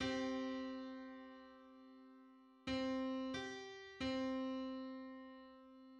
Just: 95/64 = 683.83 cents.
Public domain Public domain false false This media depicts a musical interval outside of a specific musical context.
Ninety-fifth_harmonic_on_C.mid.mp3